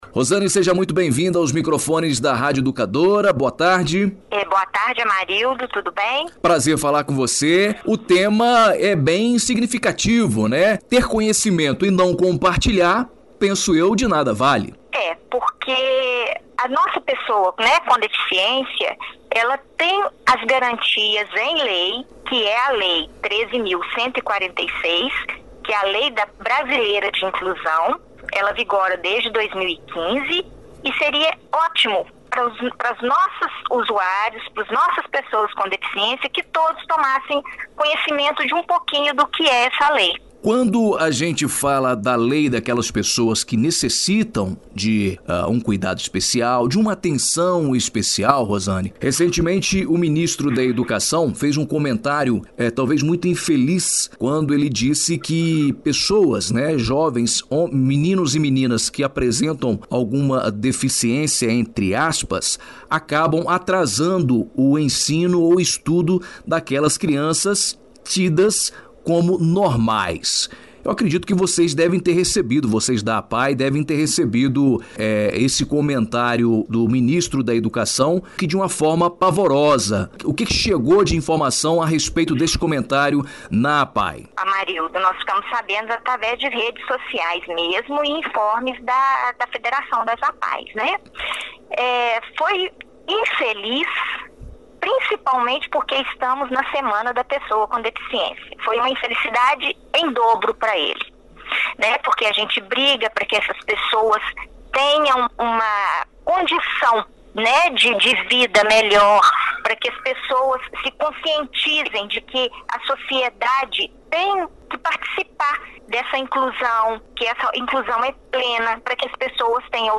em entrevista exibida no sábado(21/08), no Jornal Em dia com a Notícia da Rádio Educadora AM/FM.